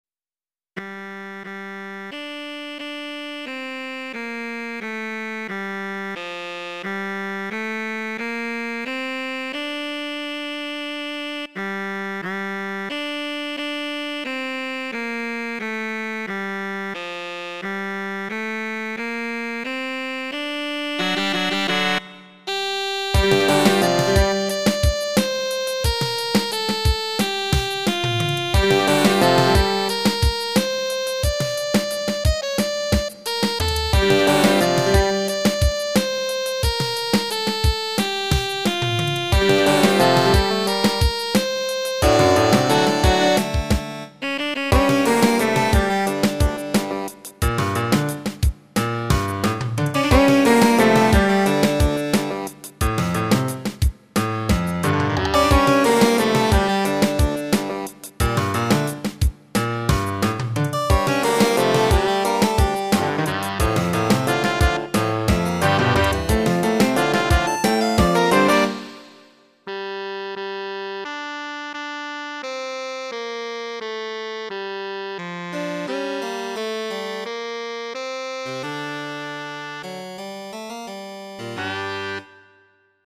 This is part of a traditional carol combined with one of my own songs.